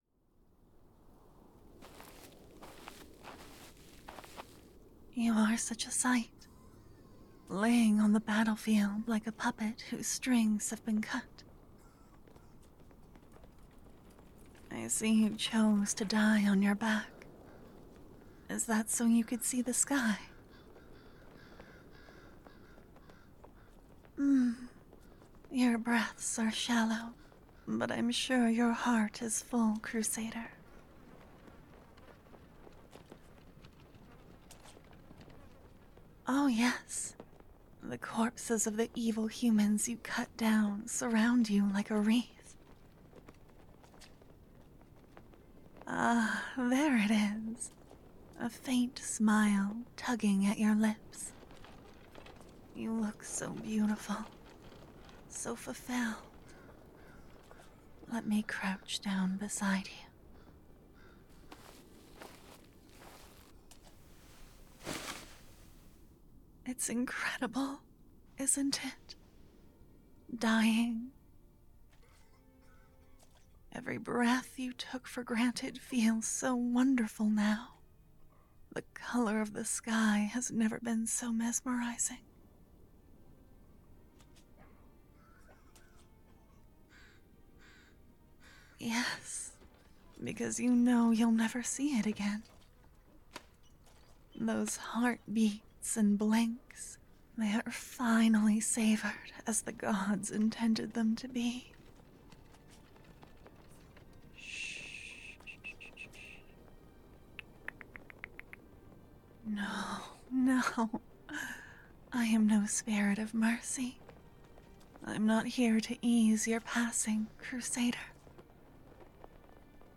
I don't have angels in the world, but I hope this still hits the mark for you~!It's just a soft sleepy little injured listener story with a loving yandere to help you fall asleep.